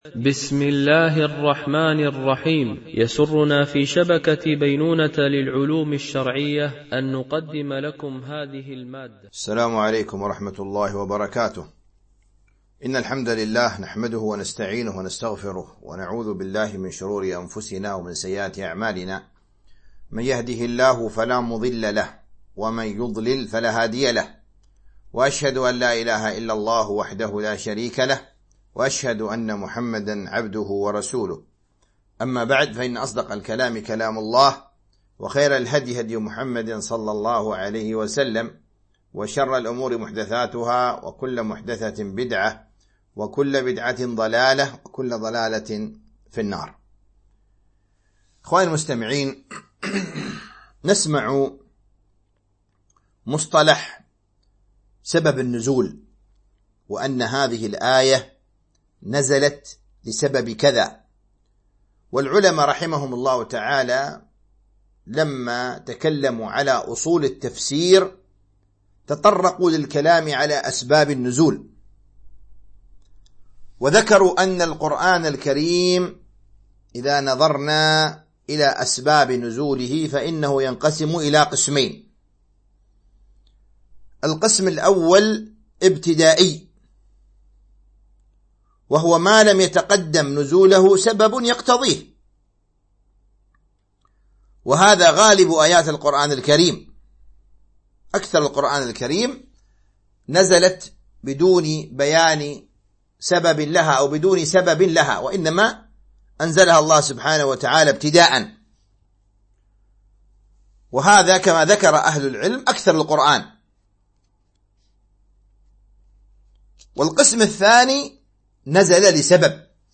التعليق على تفسير جزء عم من تفسير السعدي - الدرس 5 (سورة المطففين)